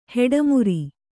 ♪ heḍa muri